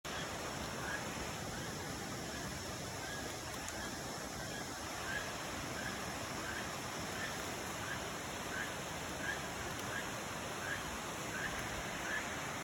More frog(?) sounds.
LaFortunaForestSounts03.mp3